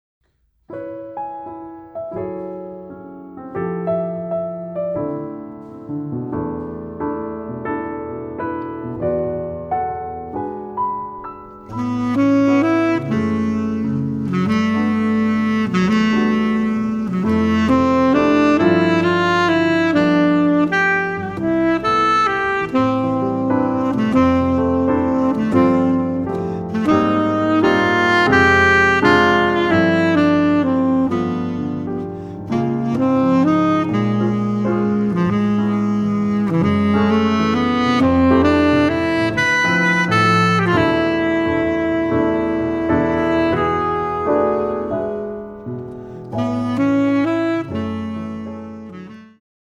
piano solo on this jazz waltz